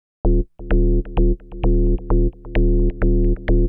bass02.wav